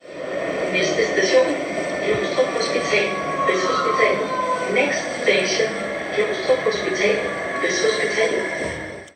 Højttalerudkald Metro og Letbane